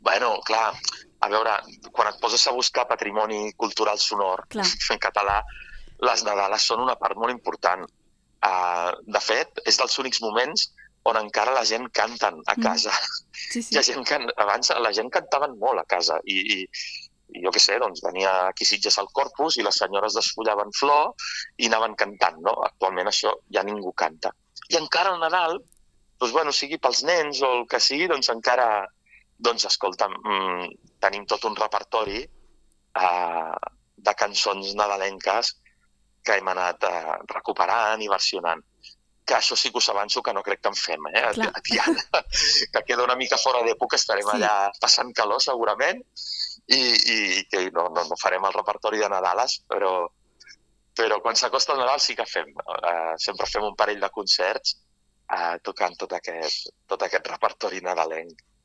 amb qui fem l’entrevista.